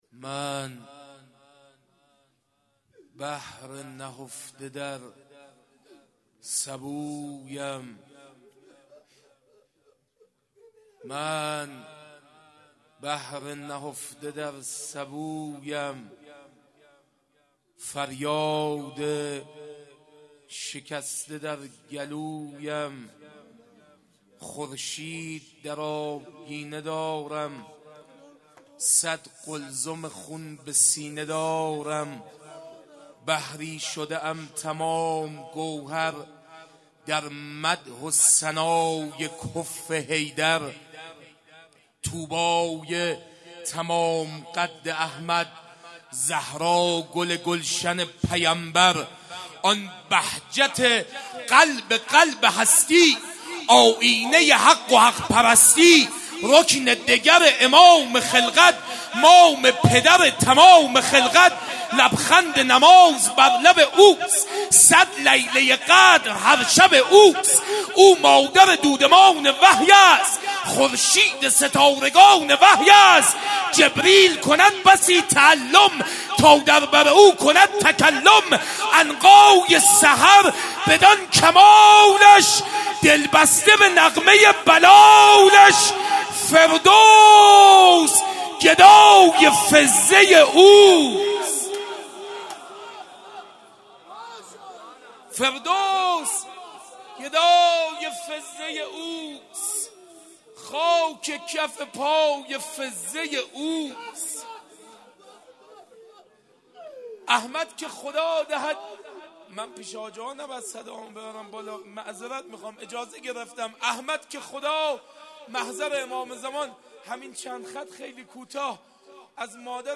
قالب : روضه